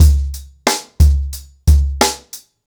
TheStakeHouse-90BPM.15.wav